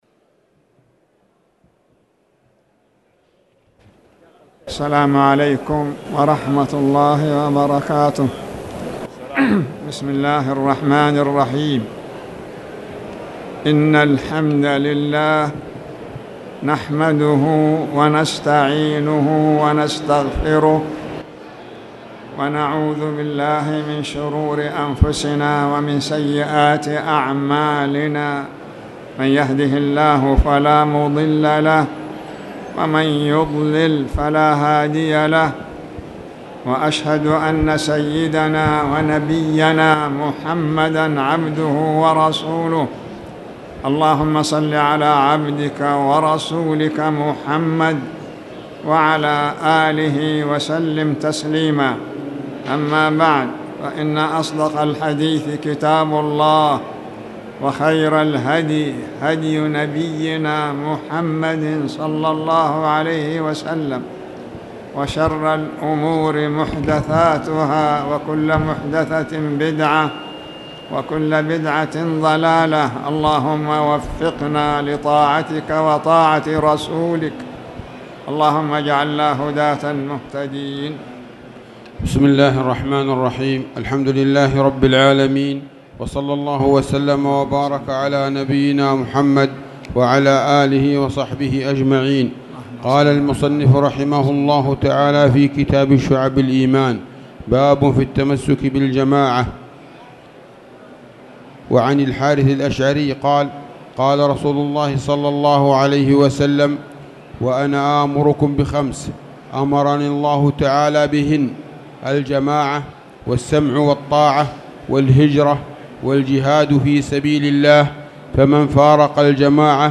تاريخ النشر ٢٩ صفر ١٤٣٨ هـ المكان: المسجد الحرام الشيخ